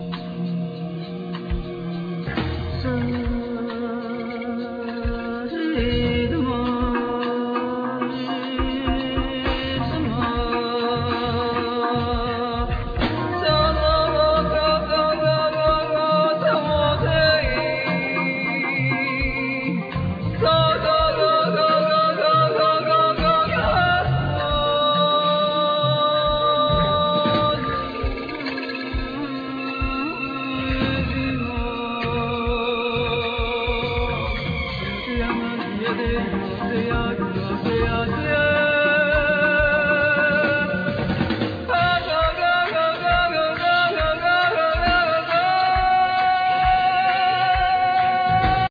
Drums,Gongs
Tabla,Percussions,Voice
Acoustic Bass
Saxophones,Flute
Electric Bass
Keyboards,Piano